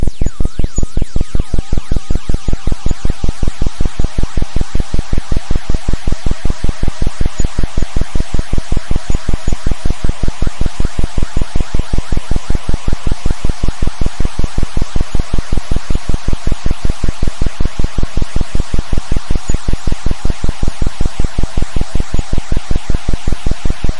描述：记录自arp2600va free vsti，随机参数算法在flstudio 3.0中
Tag: 合成器 发电机 振荡器